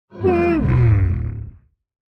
sounds / mob / sniffer / idle3.ogg